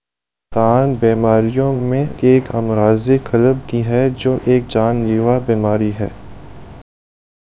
deepfake_detection_dataset_urdu / Spoofed_TTS /Speaker_09 /11.wav